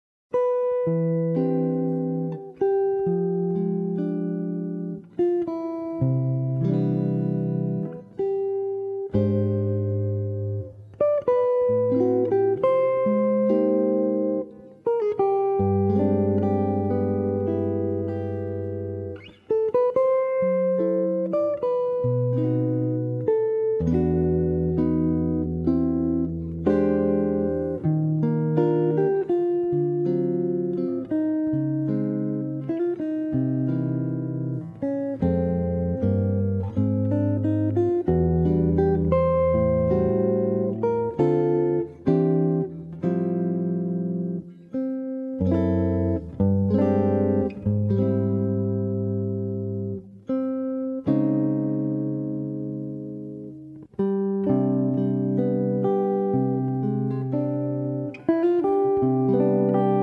performed by a jazz quintet
Guitar